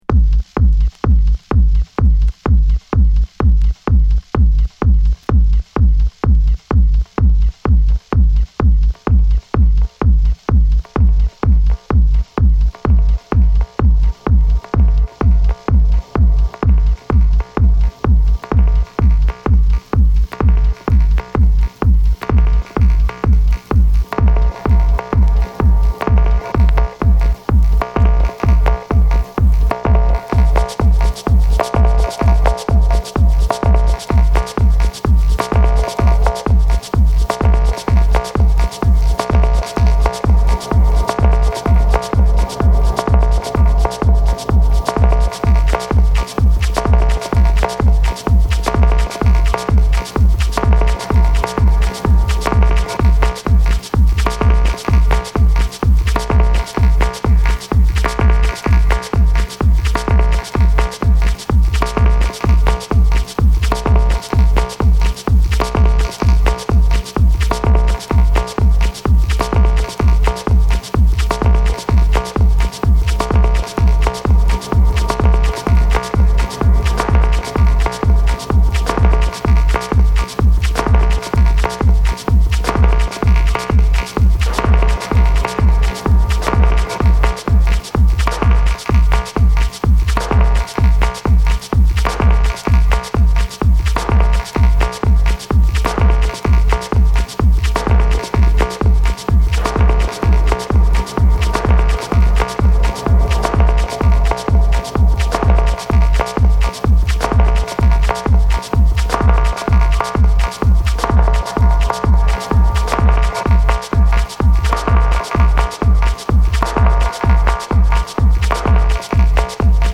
パーカッションと感電したようなベースラインでじわじわとハメるBass Musicとしても機能する